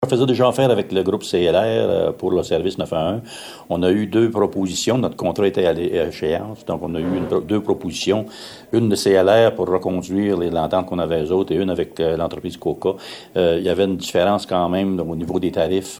Voici le maire d’Aumond, Mario Langevin :